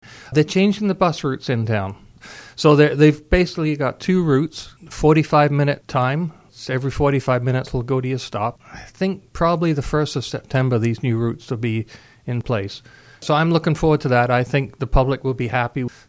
The Town of Orangeville is making two significant improvements to the transit system.  Orangeville Councillor, Andy Macintosh, explains the first significant change: